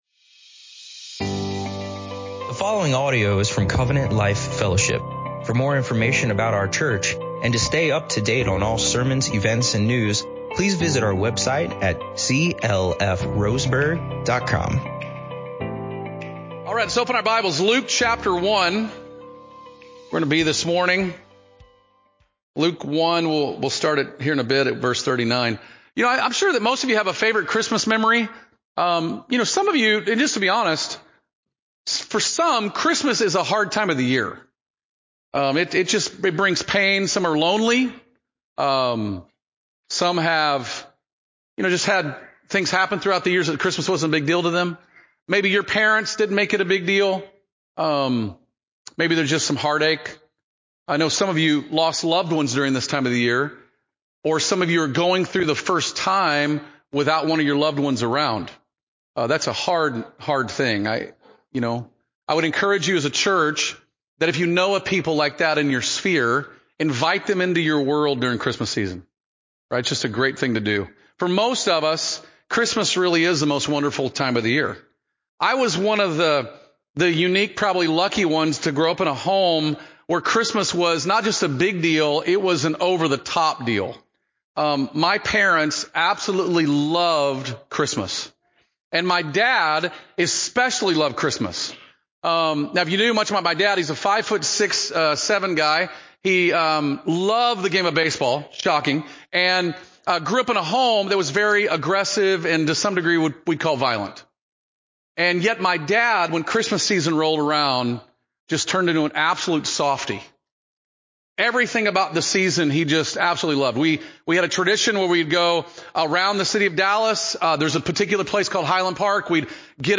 In this sermon, Joyful Expectation, we dive into Luke 1:39-56, where Mary visits Elizabeth and bursts into a song of praise.